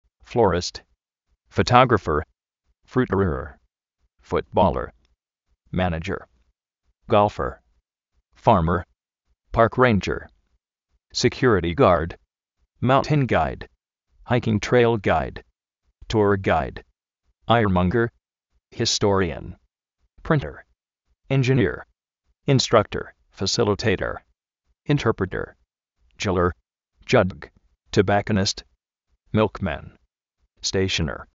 flórist
fotógrafer
futbóler
mánayer